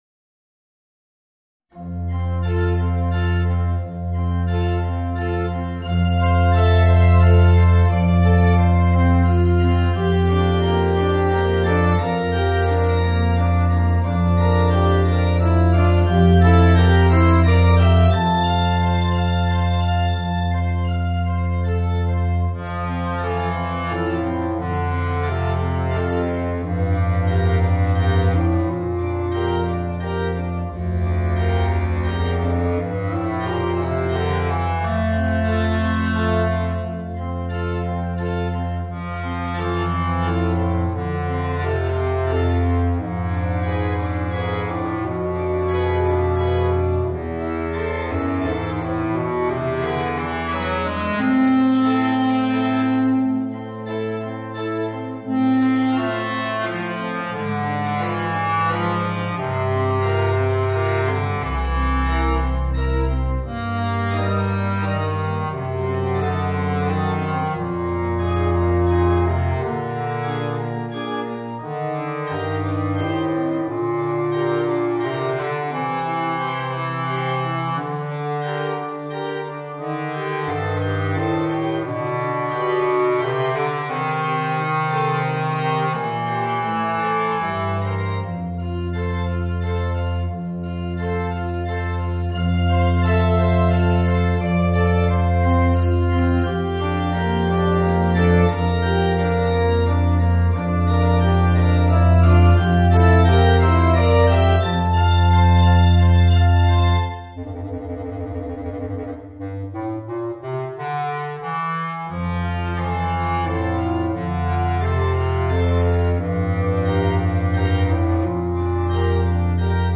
Voicing: Bass Clarinet and Piano